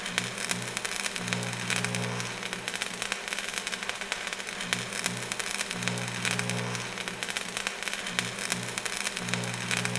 lightgrenade_spark.wav